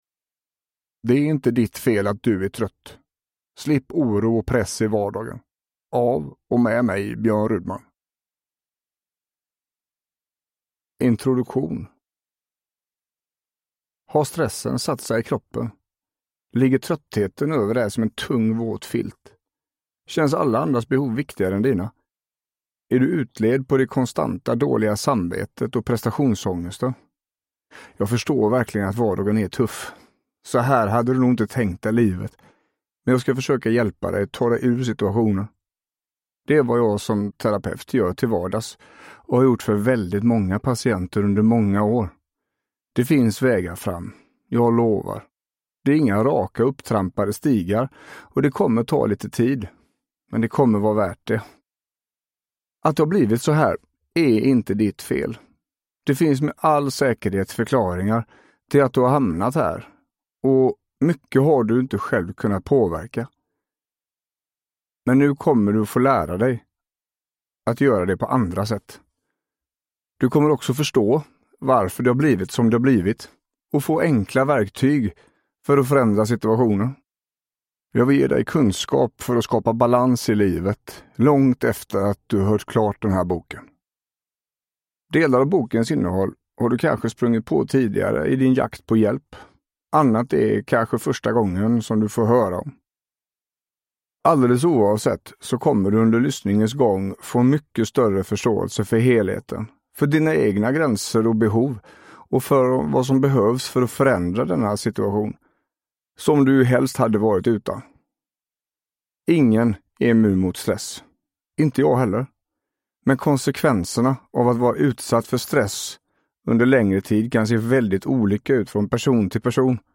Det är inte ditt fel att du är trött : slipp oro och press i vardagen – Ljudbok